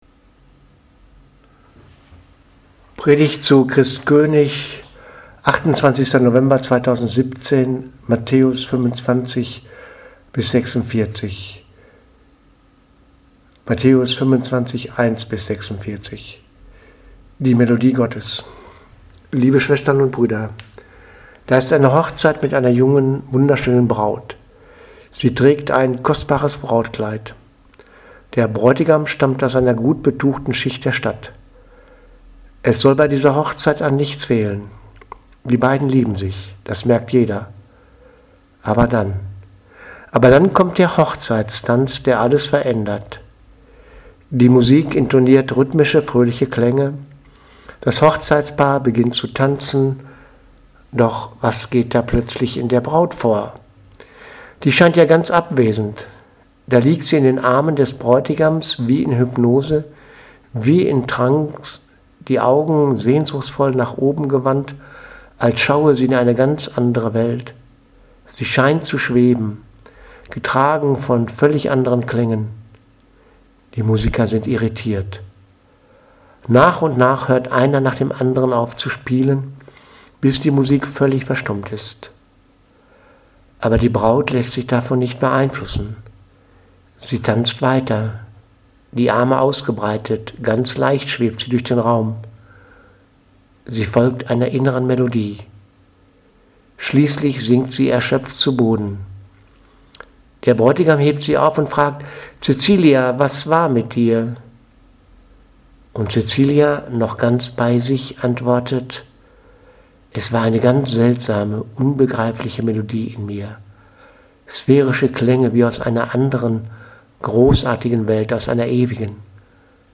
Predigt vom 28.11.2017 – Christkönig